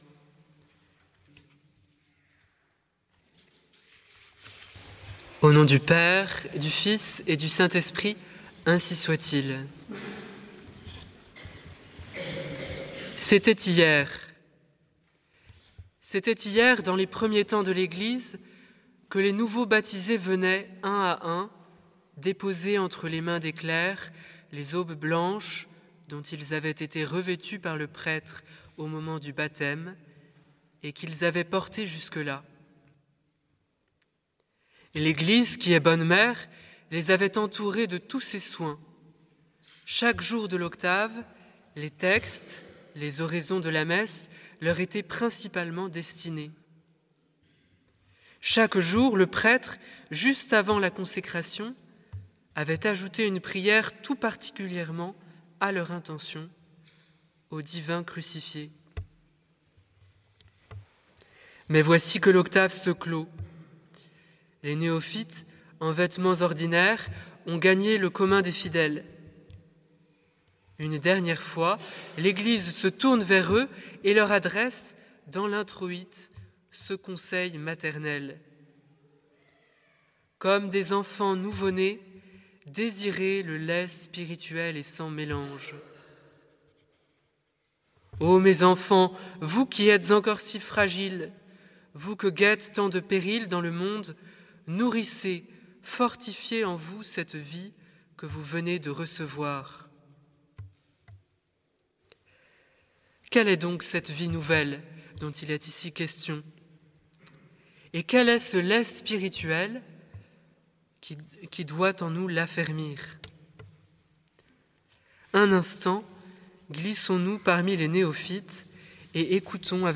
Type: Sermons Occasion: Dimanche in Albis